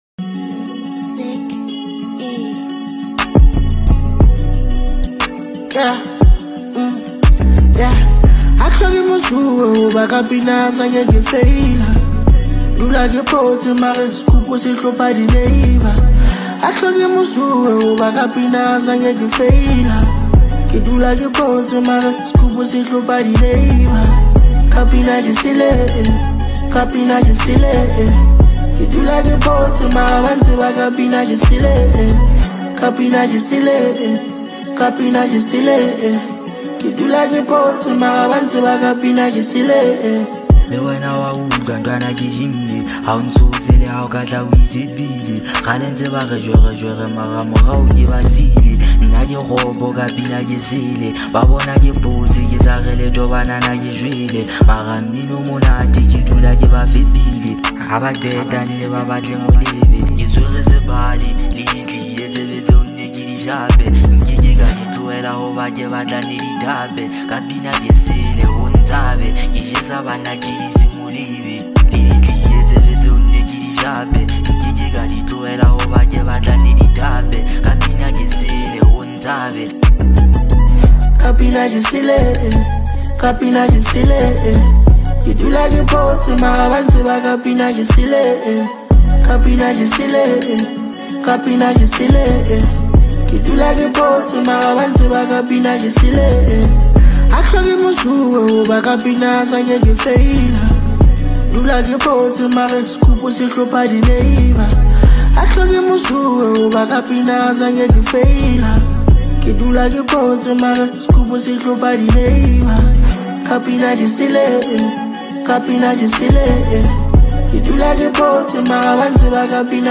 02:26 Genre : Trap Size